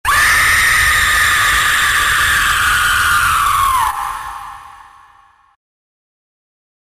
Sonic.EXE Scream